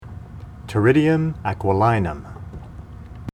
Pronunciation Cal Photos images Google images